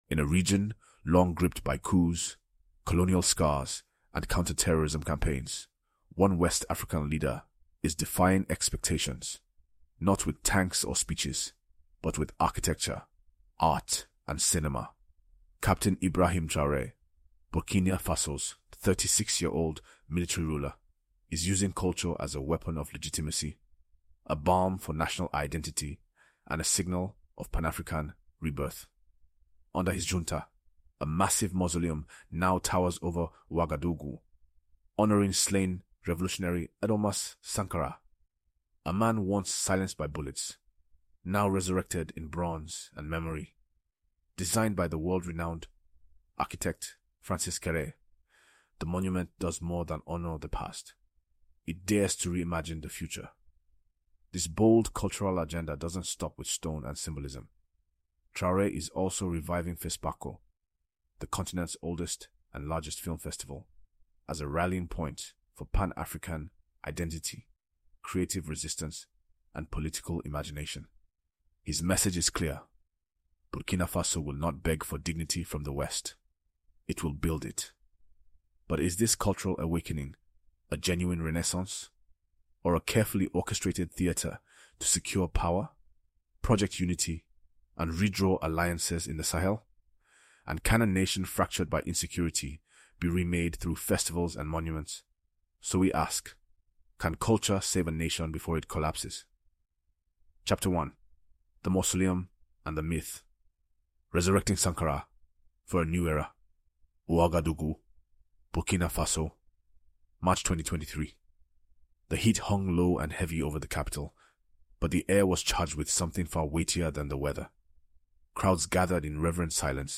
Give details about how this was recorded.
With gripping scenes, symbolic analysis, and insider interviews, this story challenges everything we think we know about coups, culture, and power in the Sahel.